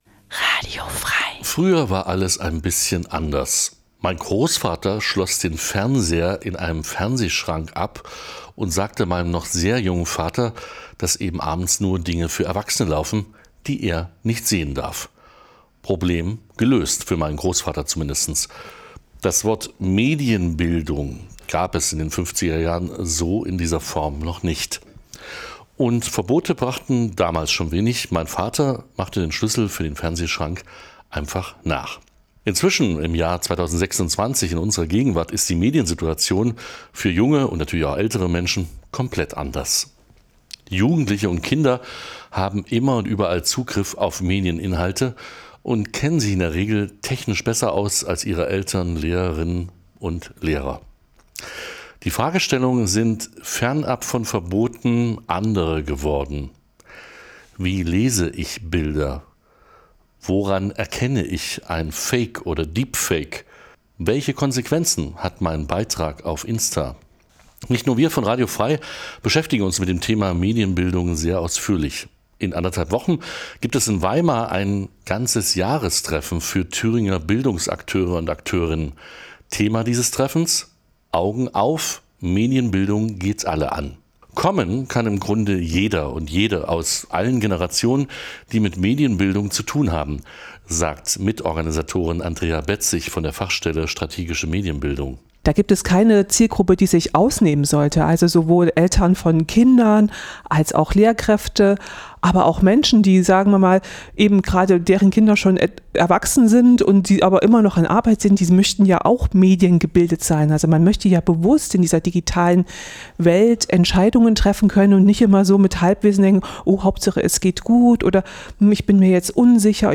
Radio F.R.E.I. berichtet über das Jahrestreffen des Medienbildungsnetzwerkes Thüringen in seinem Programm des Bürgersenders in Erfurt.
Reinhören bei Radio F.R.E.I. Interview zum anhören